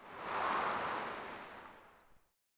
wind_gust_01.wav